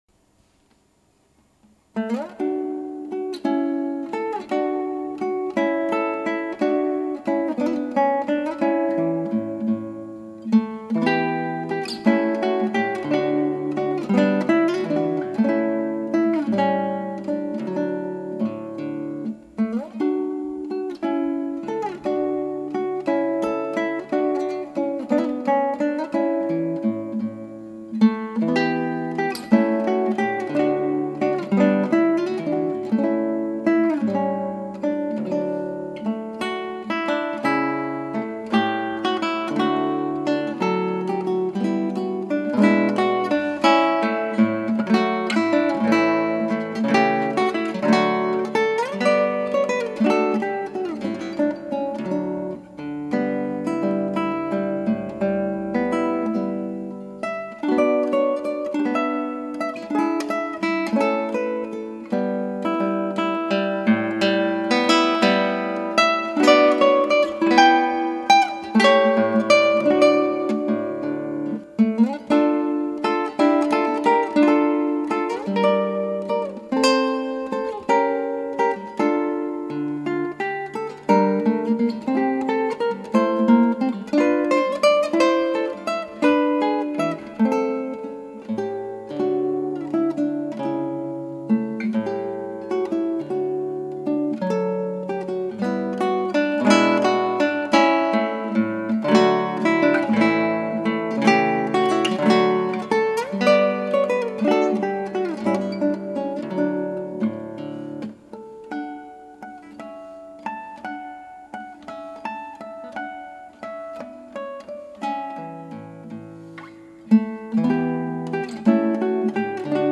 ギターの自演をストリーミングで提供
5年振りの再録音です。以前とずいぶん違う弾き方になっています。
イメージ的にはあったかい部屋で幸せなひと時を過ごす家族という感じで弾いてます。